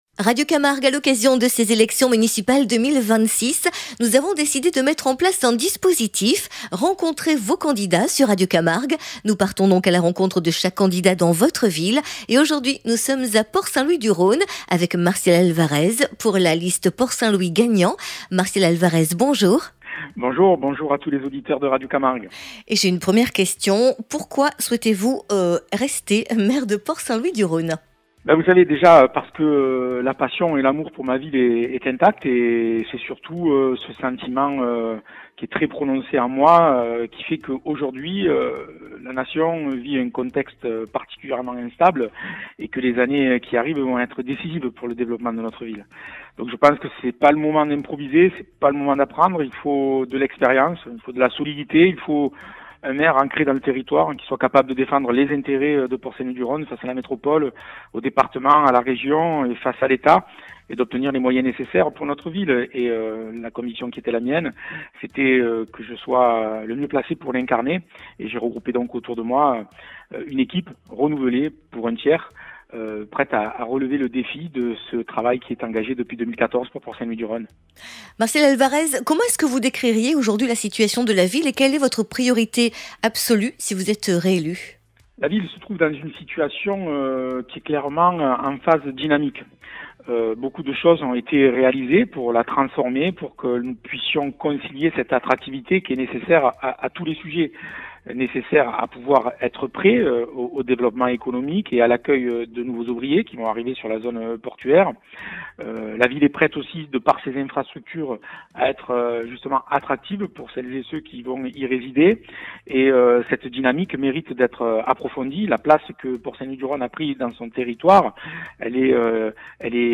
Municipales 2026 : entretien avec Martial Alvarez